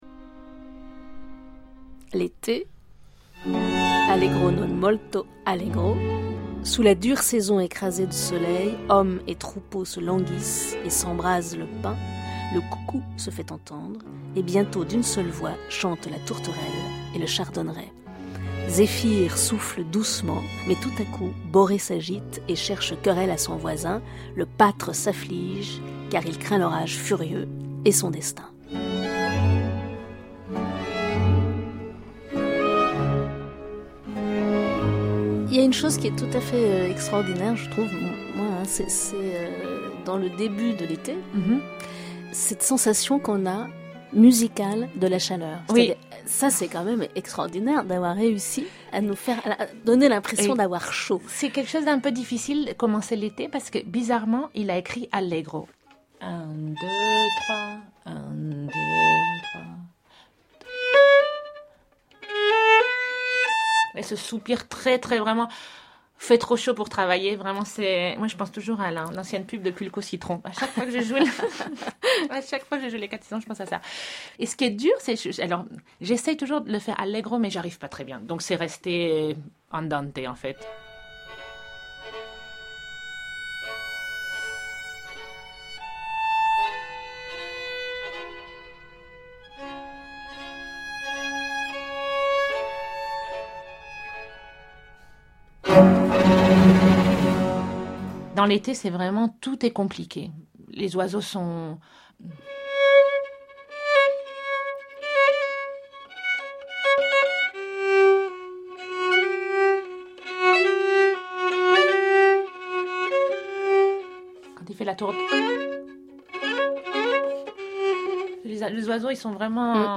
Chaque concerto possède une structure en trois mouvements : un mouvement vif, un lent et un vif.
Voici la violoniste Amandine Beyer dans un extrait de l’été.